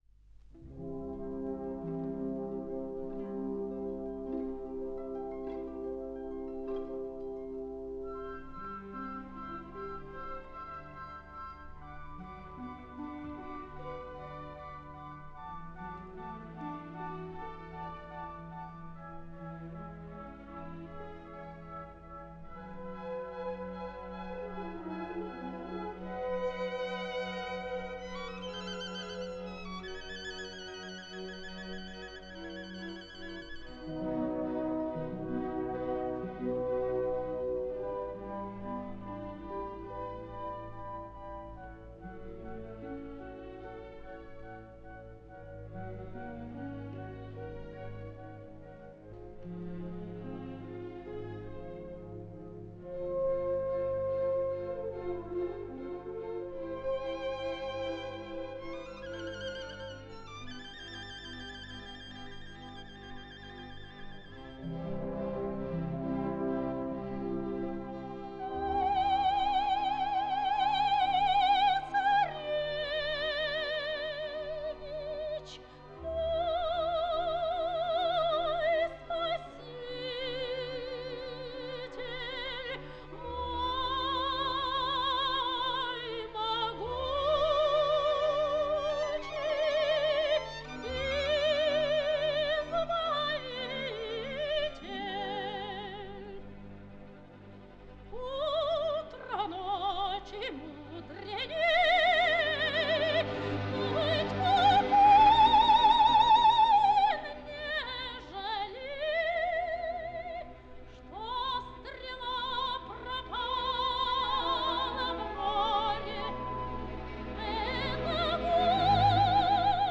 Ария_Царевны_Лебедь.mp3